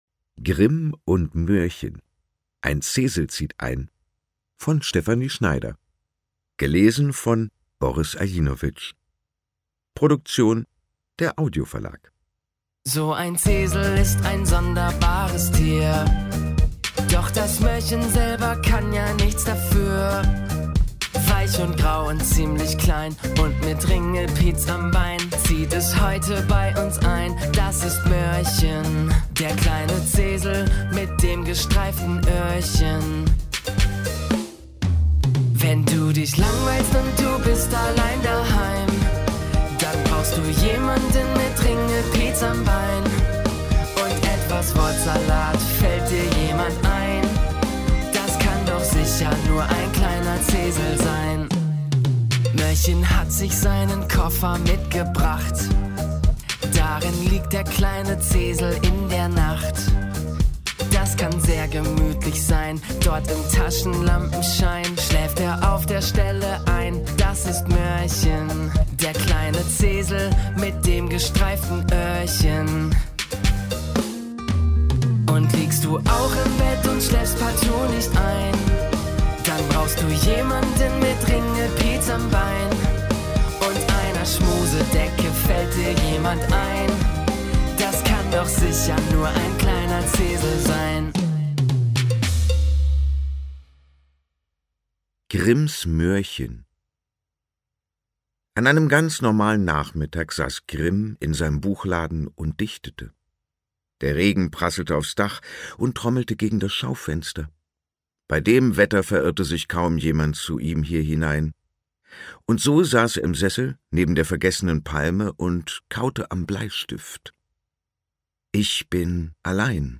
Grimm und Möhrchen – Teil 1: Ein Zesel zieht ein Ungekürzte Lesung mit Musik
Boris Aljinović (Sprecher)